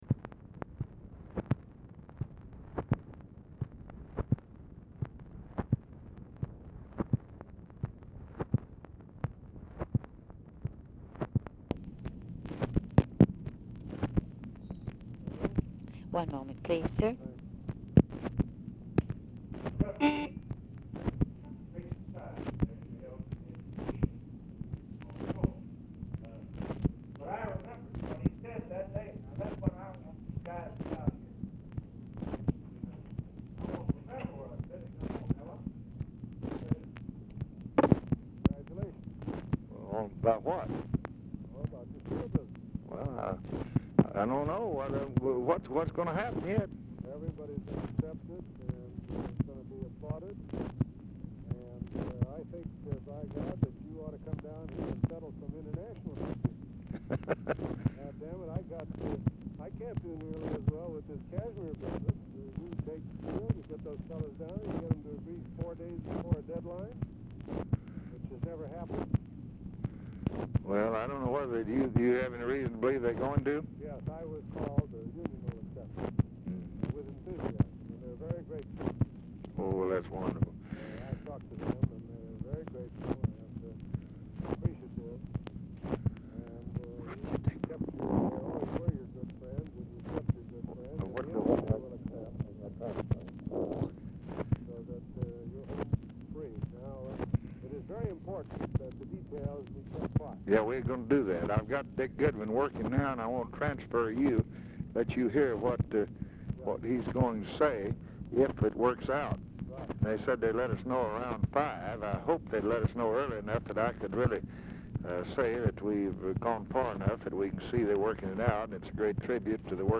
Telephone conversation # 8836, sound recording, LBJ and ARTHUR GOLDBERG, 9/3/1965, 5:01PM
POOR SOUND QUALITY DUE TO ADHESIVE RESIDUE ON DICTABELT; GOLDBERG IS DIFFICULT TO HEAR; INAUDIBLE OFFICE CONVERSATION PRECEDES CALL